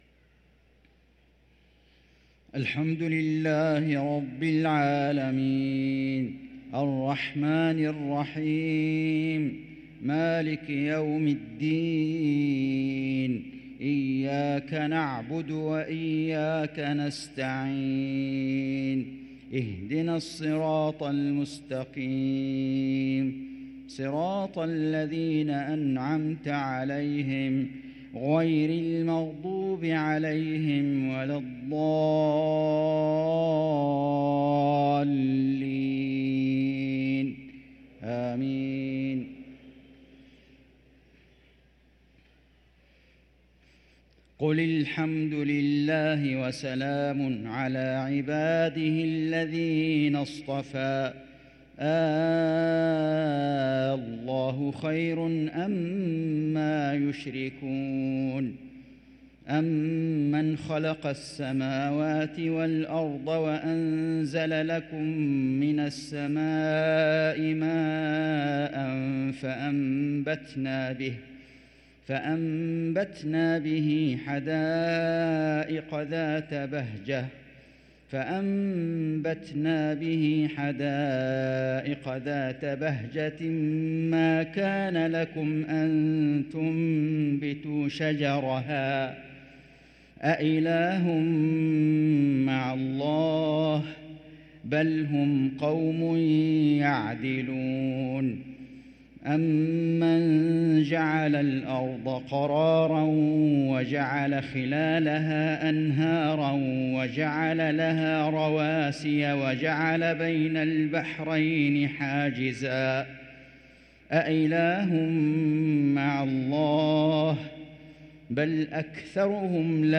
صلاة العشاء للقارئ فيصل غزاوي 19 رجب 1444 هـ
تِلَاوَات الْحَرَمَيْن .